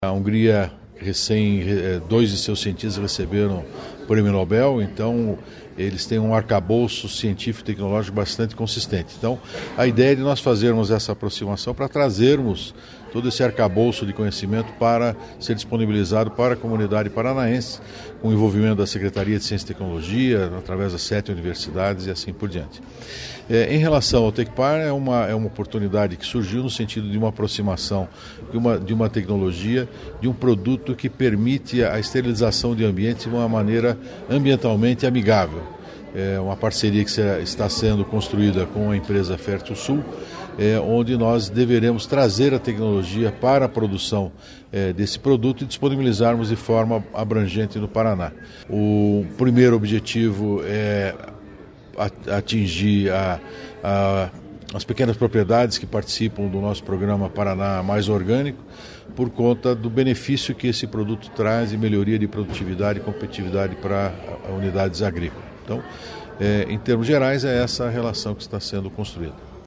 Sonora do diretor-presidente do Tecpar, Celso Kloss, sobre a parceria do Paraná e Hungria nas áreas de inovação e tecnologia